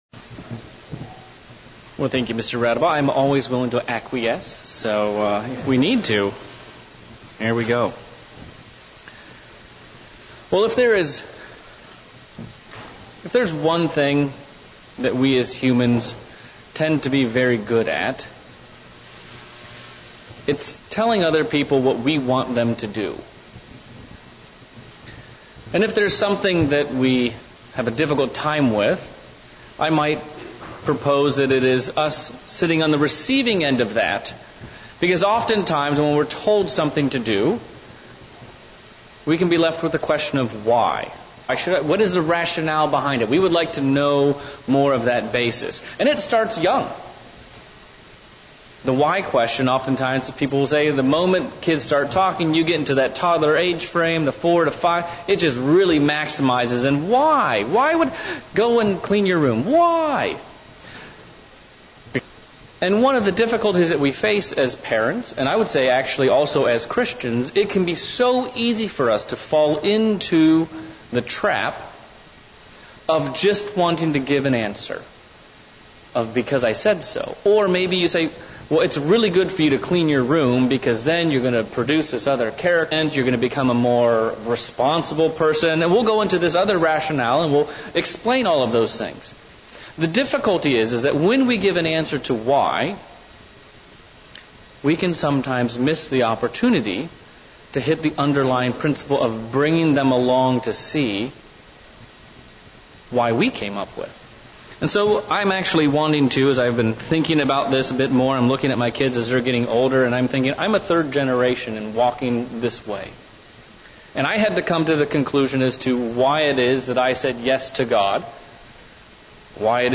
Sermon looking at the question of "Does God Exist"?